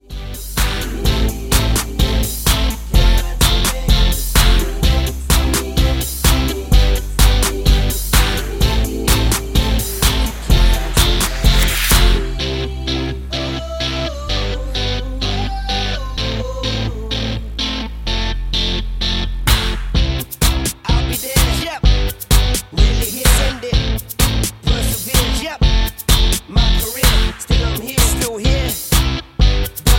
MPEG 1 Layer 3 (Stereo)
Backing track Karaoke
Pop, 2000s